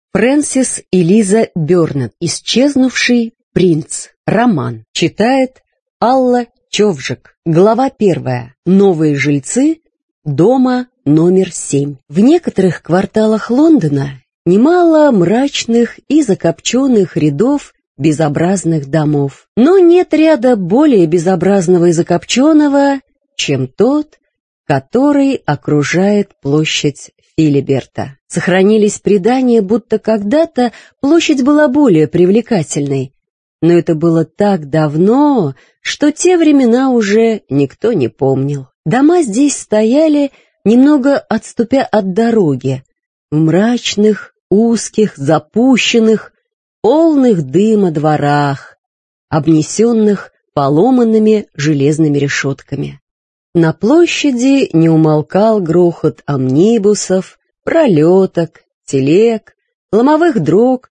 Аудиокнига Исчезнувший принц | Библиотека аудиокниг